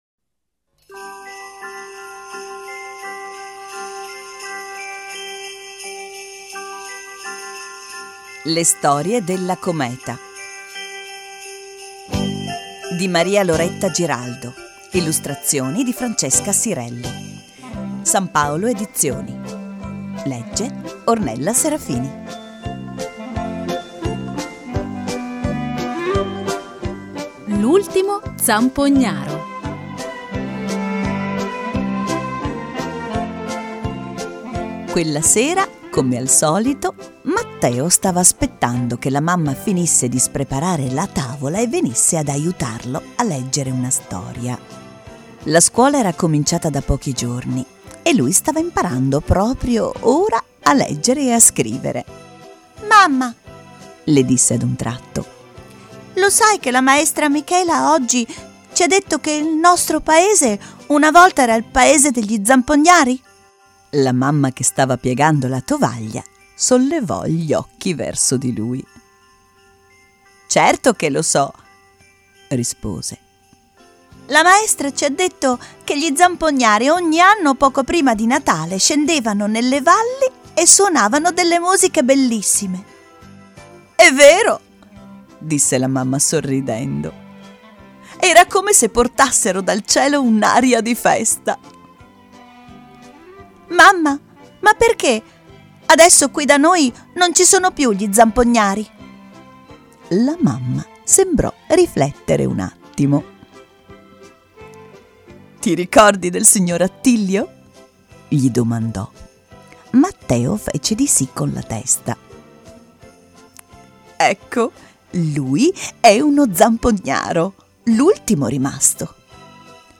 Silvana Gandolfi dà voce alla Stella Cometa che racconta 10 storie sui veri valori del Natale